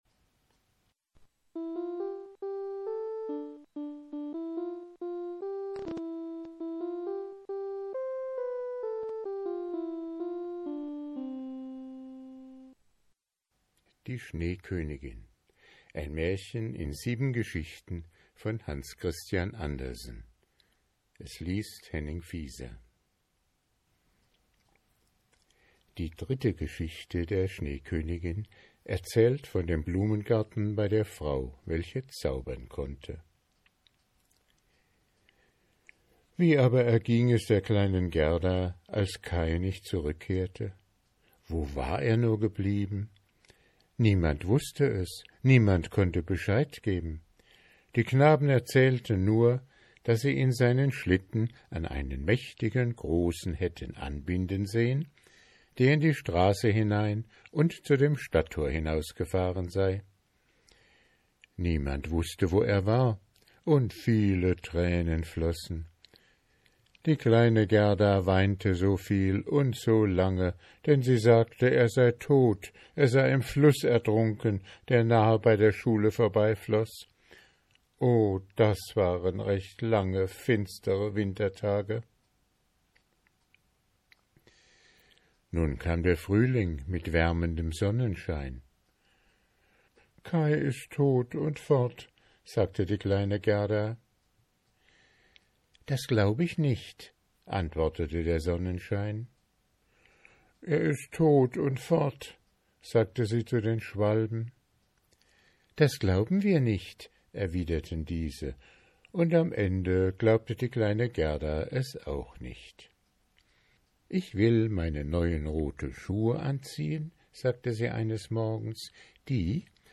Vorlesezeit 83 min ✓ Alle Grimm und Andersen Märchen in Originalfassung ✓ Online Märchenbuch mit Illustrationen ✓ Nach Lesedauer sortiert ✓ Mp3-Hörbücher ✓ Ohne Werbung